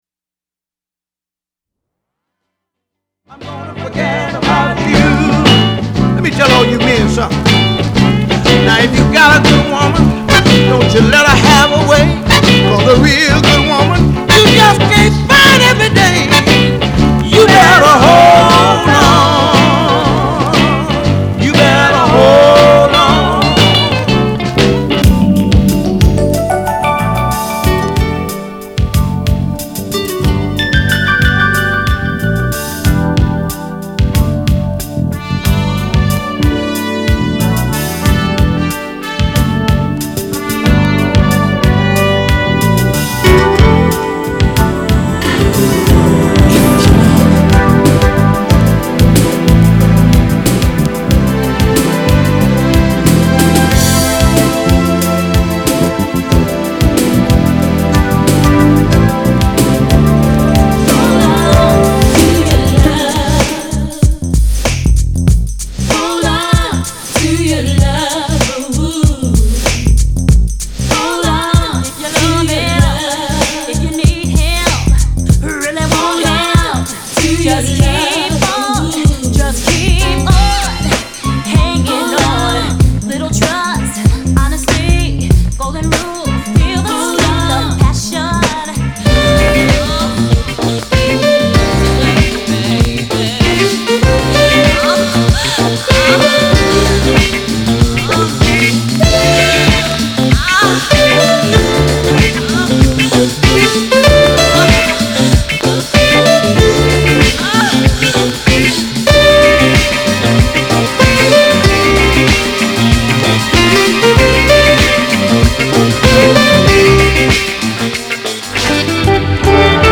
R&B、ソウル
/盤質/両面やや傷あり/US PRESS